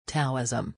Taoism or Daoism ( /ˈt.ɪzəm/